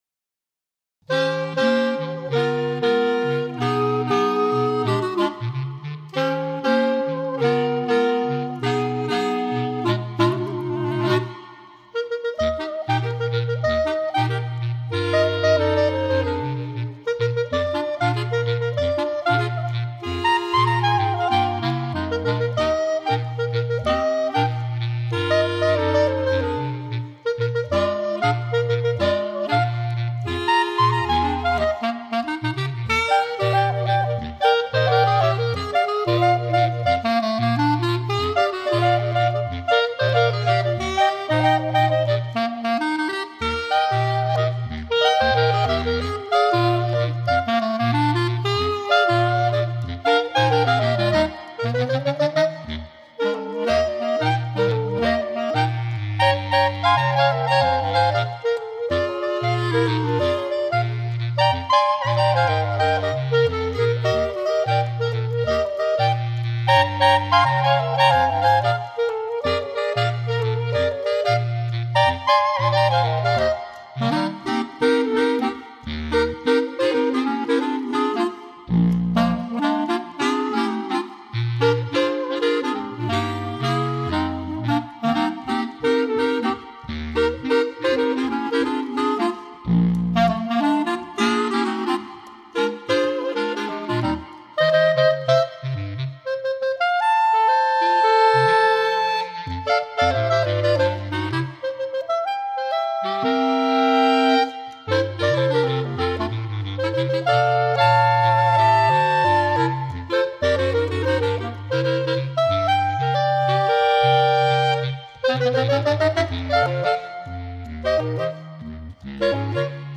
Bb Clarinet Range: E1 to D3.